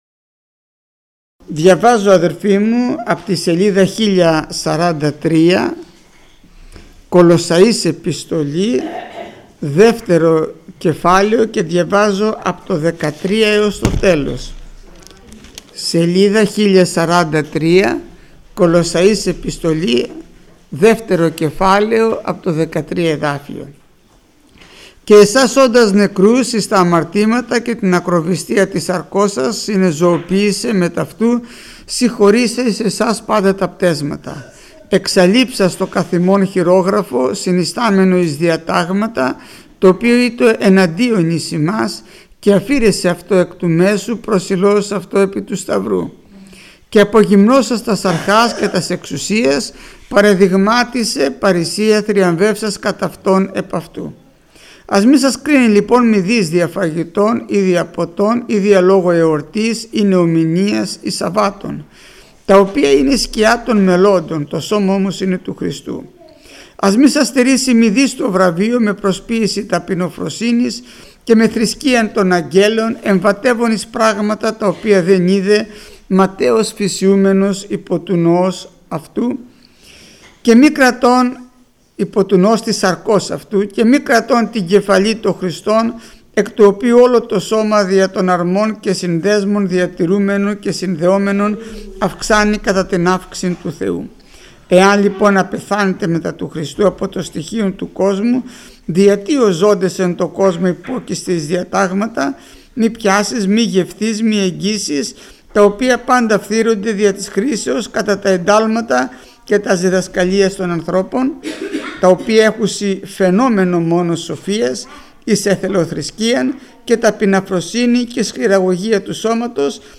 Μήνυμα πριν τη θεία κοινωνία
Μηνύματα Θείας Κοινωνίας